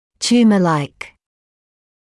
[‘t(j)uːməlaɪk][‘т(й)уːмэлайк]опухолеподобный